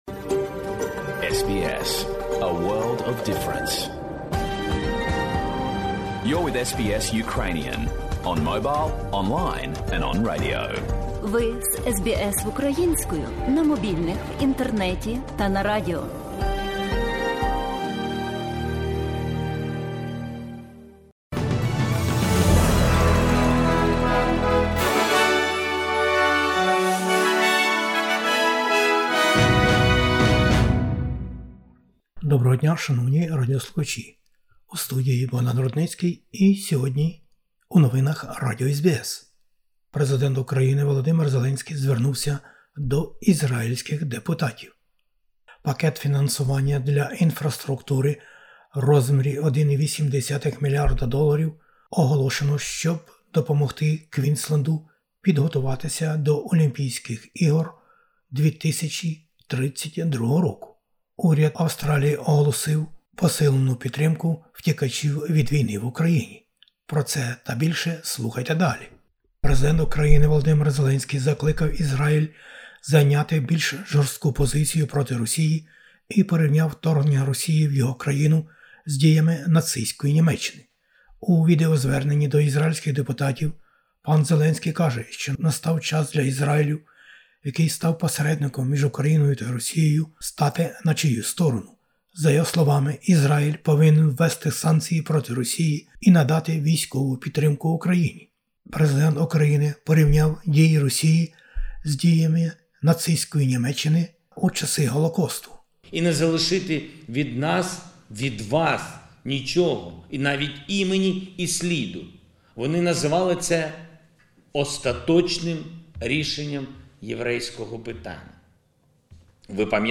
Новини SBS українською.